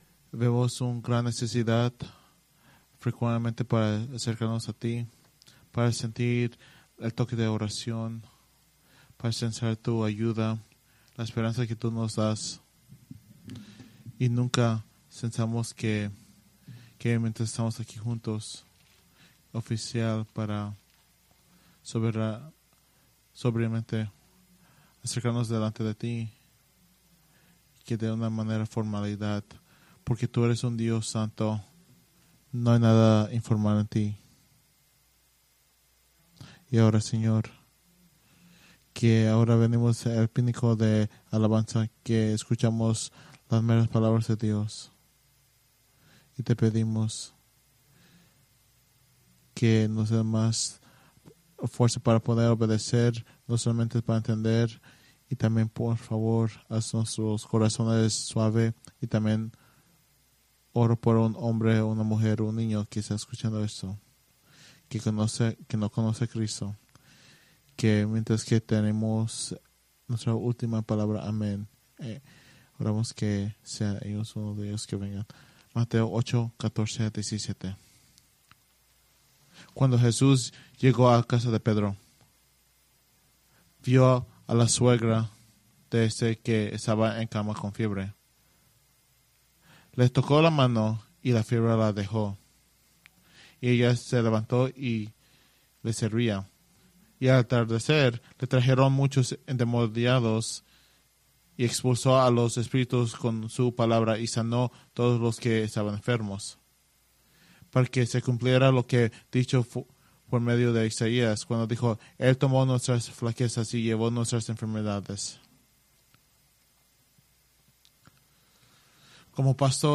Preached September 15, 2024 from Mateo 8:14-17